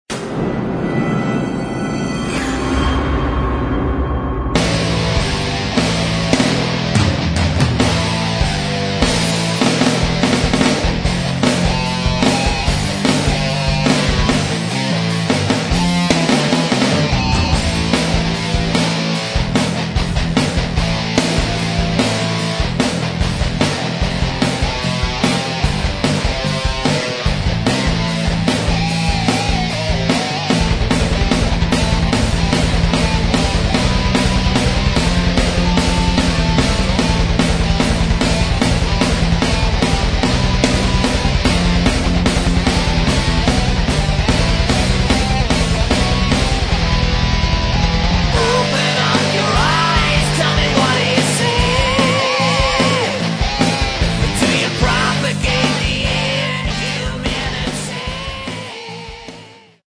Metal
это однозначно направленный мелодичный тяжёлый металл